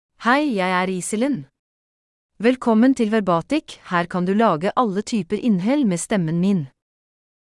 Iselin — Female Norwegian Bokmål (Norway) AI Voice | TTS, Voice Cloning & Video | Verbatik AI
Iselin — Female Norwegian Bokmål AI voice
Iselin is a female AI voice for Norwegian Bokmål (Norway).
Voice sample
Listen to Iselin's female Norwegian Bokmål voice.
Iselin delivers clear pronunciation with authentic Norway Norwegian Bokmål intonation, making your content sound professionally produced.